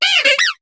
Cri de Chaglam dans Pokémon HOME.